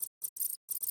beeps2.ogg